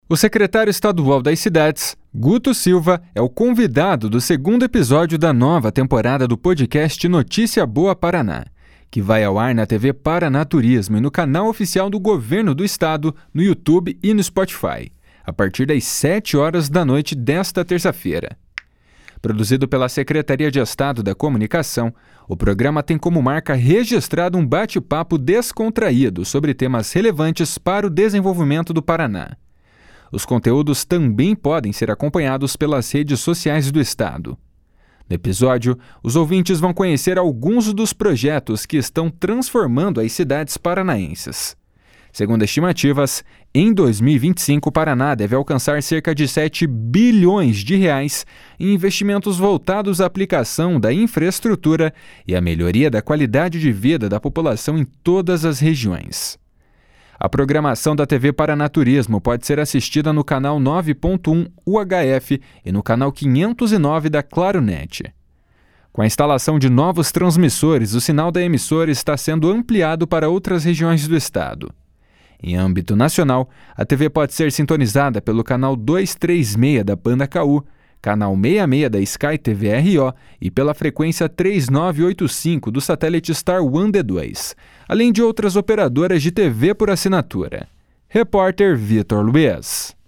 O secretário estadual das Cidades, Guto Silva, é o convidado do segundo episódio da nova temporada do podcast Notícia Boa Paraná, que vai ao ar na TV Paraná Turismo e no canal oficial do Governo do Estado no YouTube e no Spotify, a partir das 19h desta terça-feira.